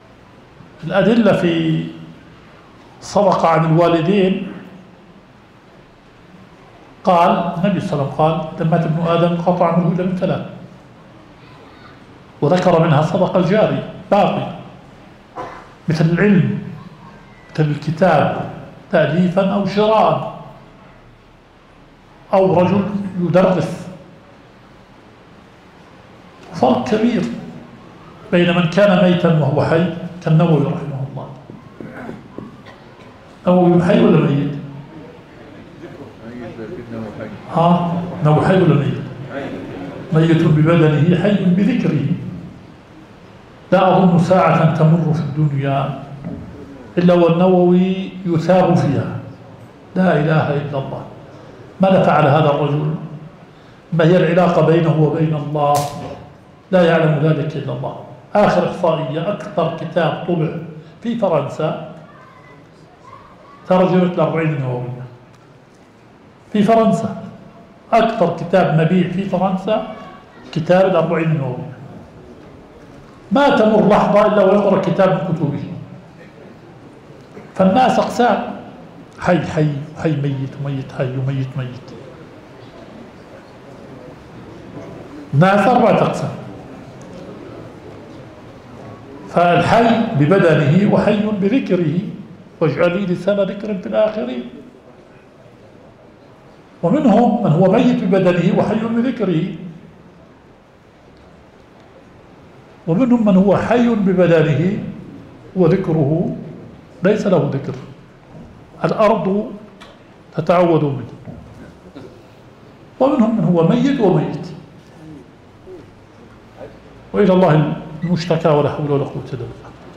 البث المباشر – لدرس شيخنا شرح صحيح مسلم